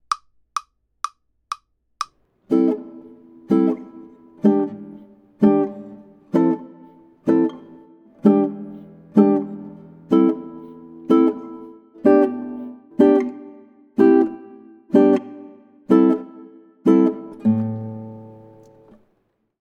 • Fast down strum on beats 2 and 4
• Mute strings on beats 1 and 3
Pie in the Sky | Jamaican strum (no melody)